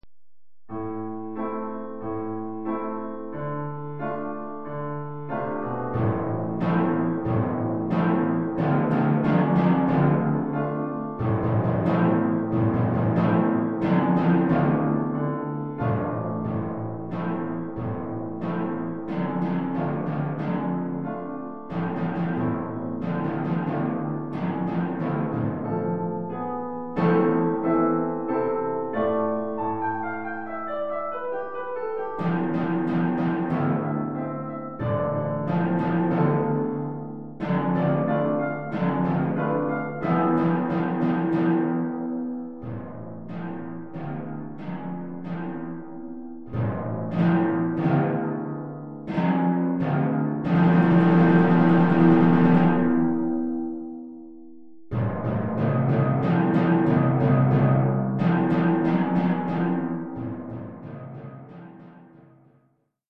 Oeuvre pour timbales et piano.
Niveau : débutant.
4 Timbales La Ré Mi Fa.